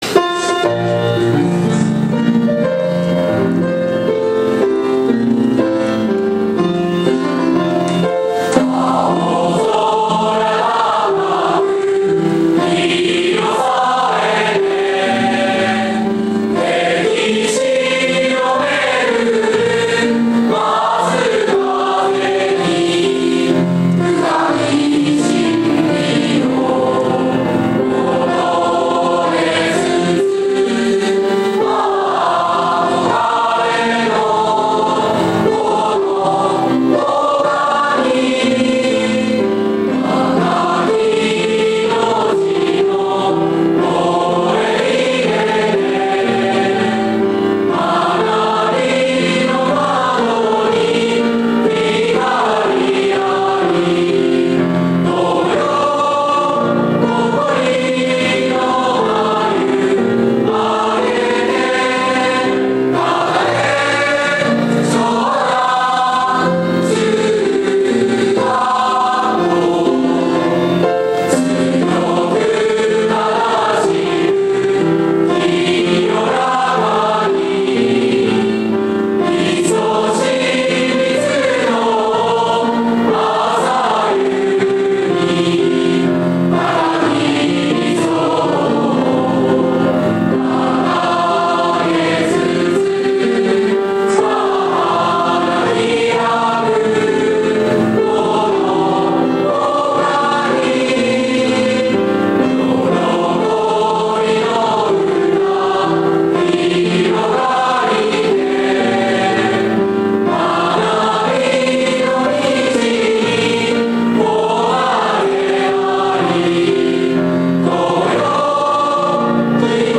校歌 - 長南町立長南中学校
作詞 ： 北町一郎 作曲 ： 寺内 昭 一、 青空たかく色さえて 歴史をめぐる松風に 深き真理を求めつつ ああ あこがれのこの岡に 若き生命のもえいでて 学びの窓に光あり 友よ 誇りの眉あげて たたえん 長南中学校 二、 強く正しく清らかに いそしみ集う朝夕に 高き理想をかがげつつ ああ 花ひらくこの岡に よろこびの歌ひろがりて 学びの道に誉れあり 友よ 希望の胸はりて たたえん 長南中学校 長南中校歌(H30 卒業式) (mp3).mp3